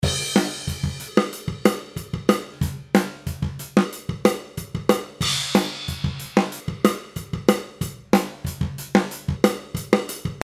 今回、ドラムにかけてみたんですけど、いいか悪いかは別としてわかりやすくはありました。
次に波形を矩形波にしてみました。
同じ周期なのに波形の違いでこうもかかり具合が違うんですね。